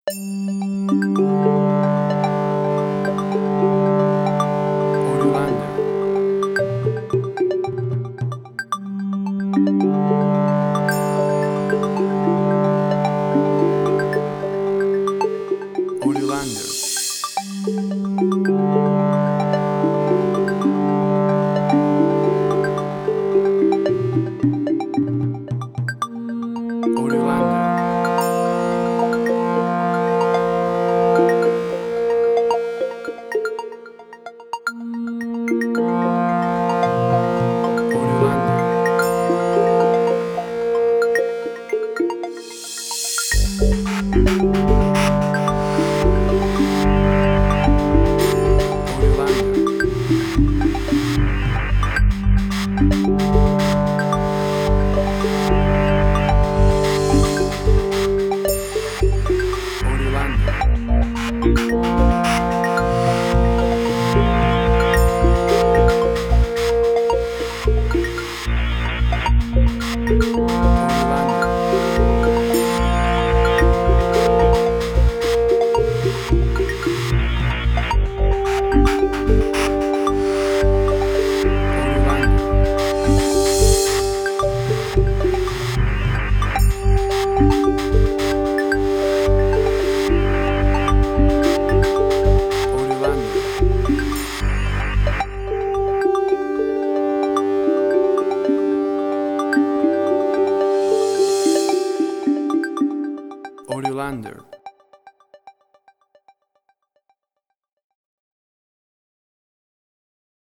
Post-Electronic.
royalty free music
WAV Sample Rate: 16-Bit stereo, 44.1 kHz
Tempo (BPM): 55